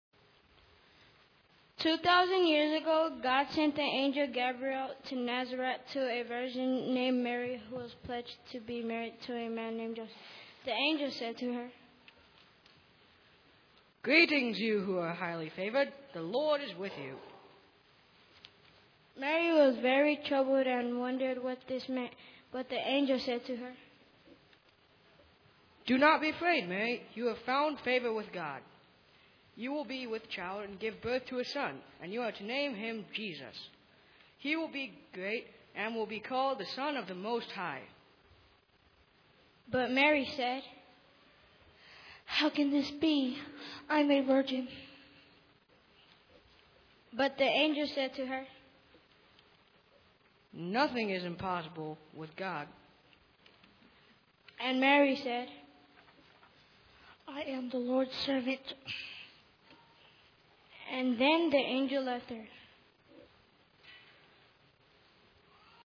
Burns Chancel Choir Cantata
Narrated by the Burns Youth